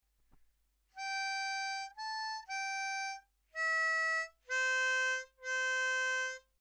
It’s a Sea Shanty sung by Sailors and Pirates.
We have chopped the tune up into small chunks to help you.